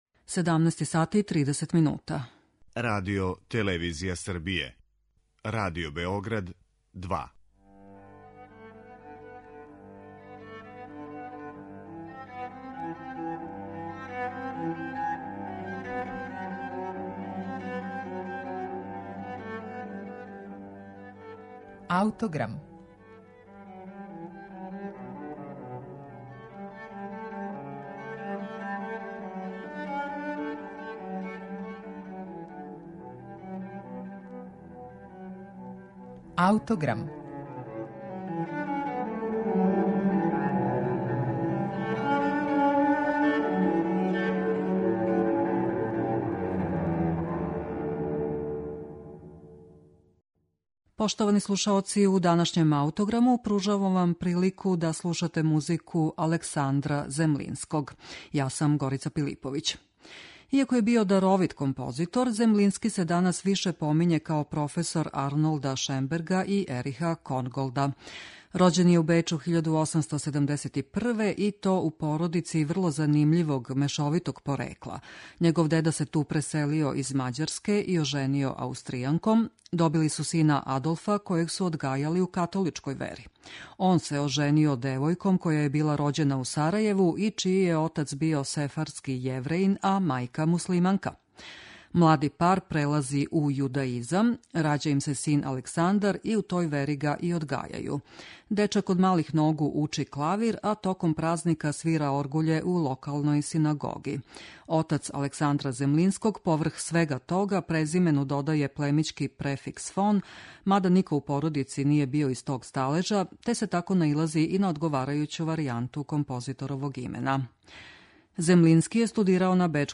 Слушаћемо његов Трио за кларинет, виолончело и клавир.